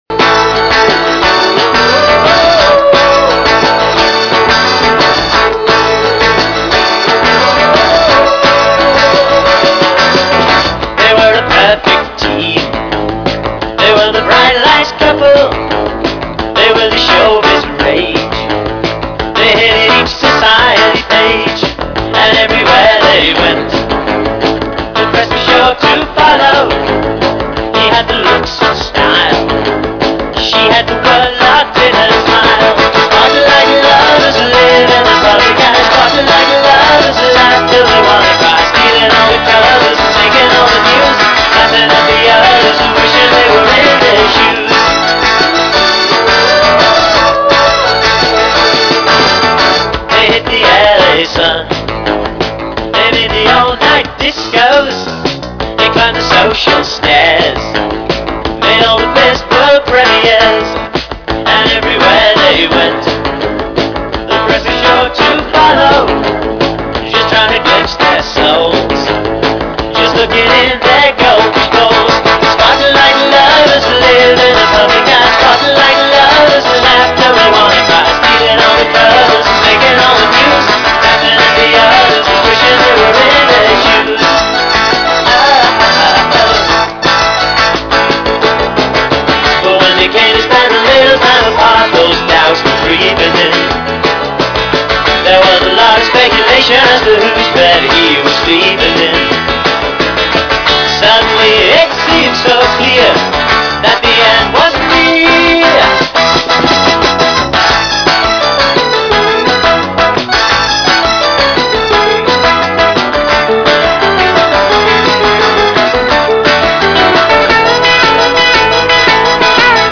pure power pop single。